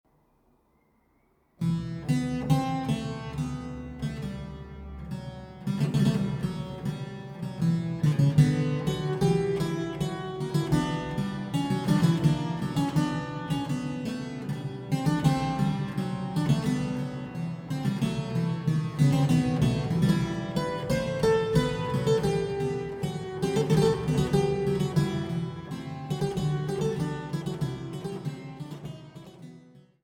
Praeludium h-Moll